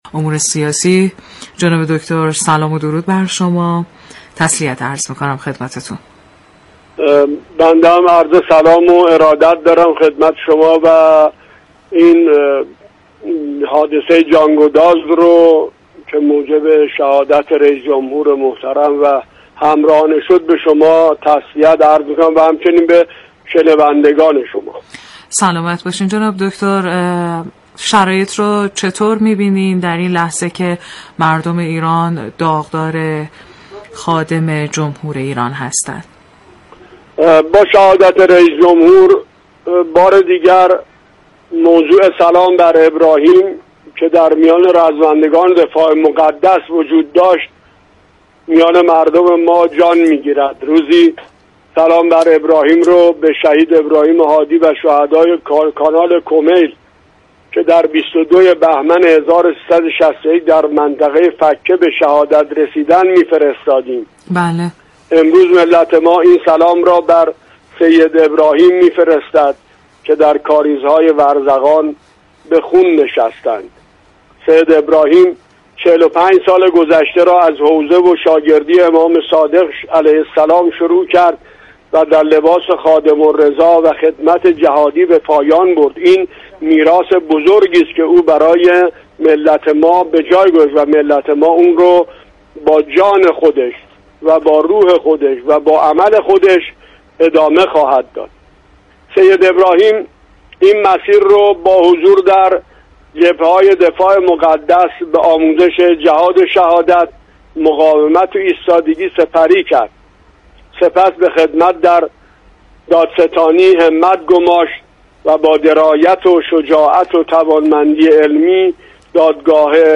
دكتر هادی طحان نظیف سخنگوی شورای نگهبان در برنامه "خادم الرضا" رادیو نمایش: